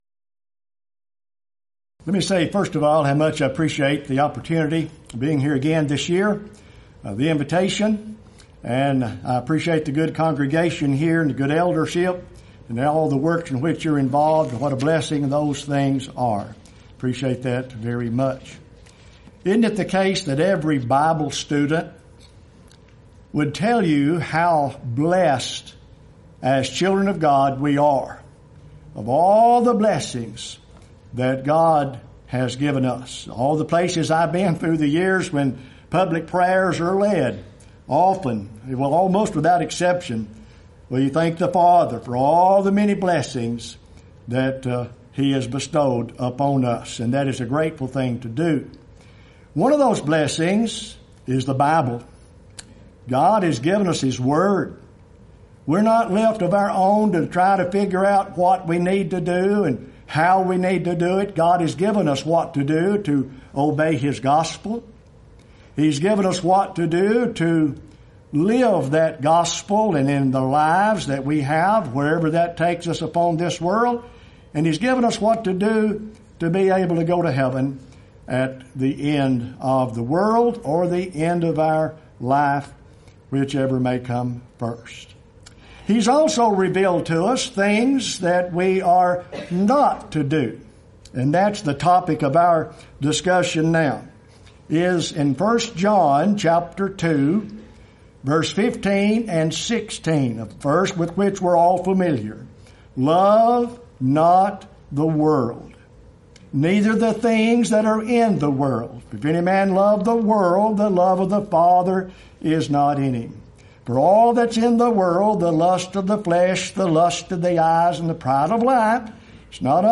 Event: 26th Annual Lubbock Lectures Theme/Title: God is Love